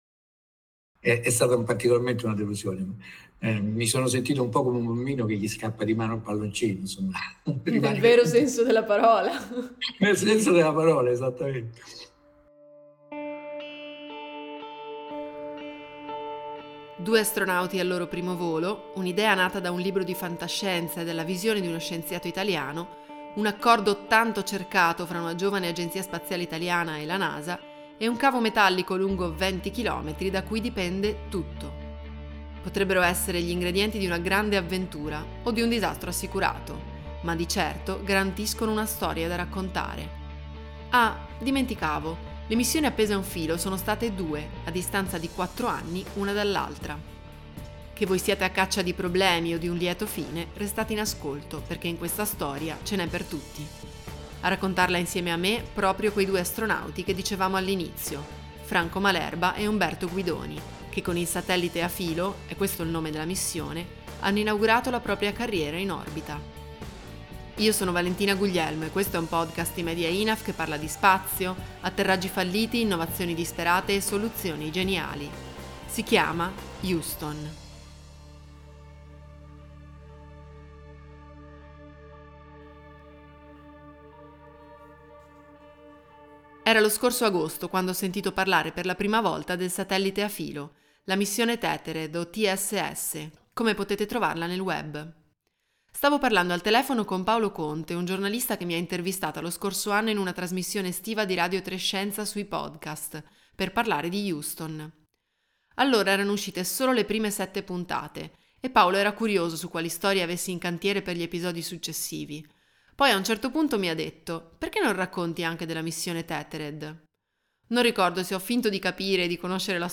I due viaggi del satellite Tethered, il satellite a filo, sono forse poco conosciuti, ma i due astronauti Franco Malerba e Umberto Guidoni ve la racconteranno assieme a me.